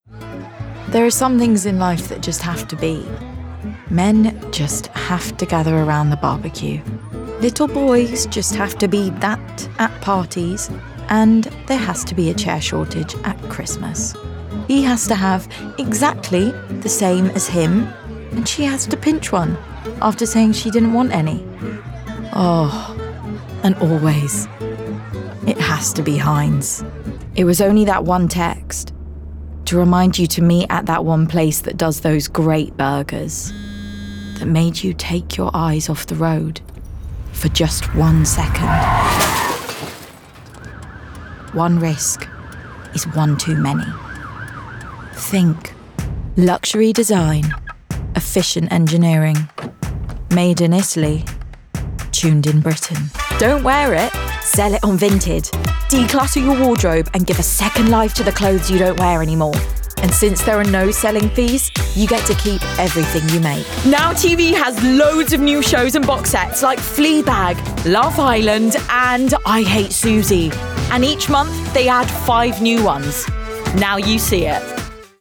Commercial Reel
RP ('Received Pronunciation')
Commercial, Conversational, Bright, Upbeat, Natural